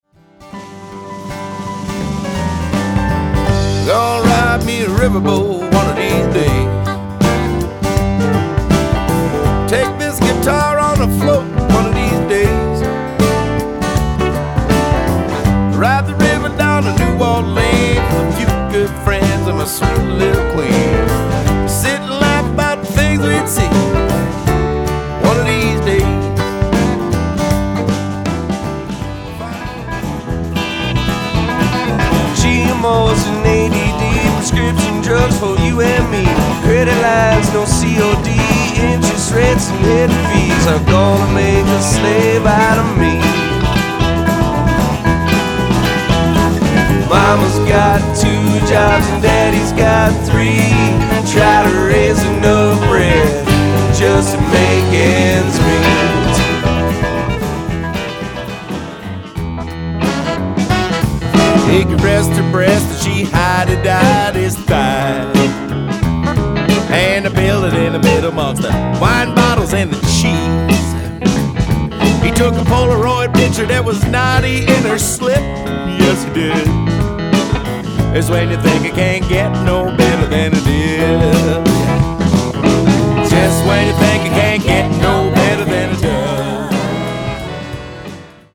Genre/Style: Rock, roots rock, jamband